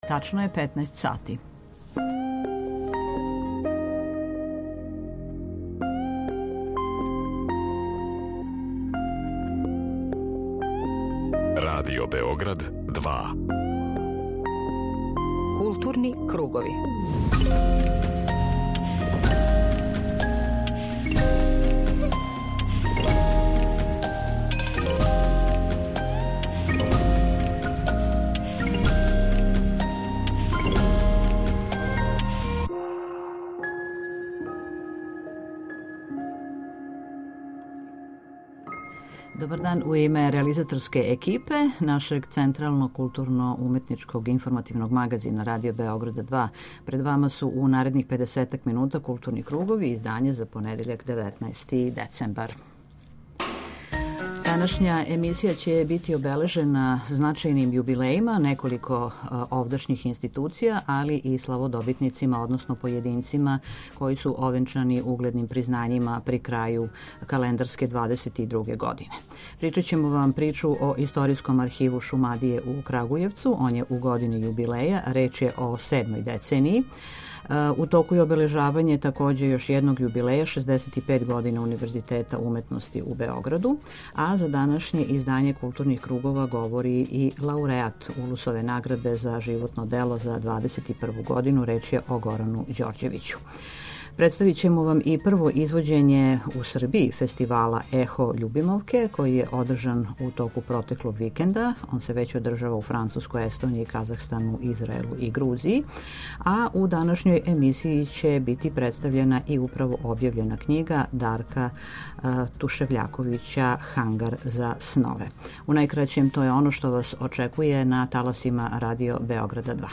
Дневни културно-информативни магазин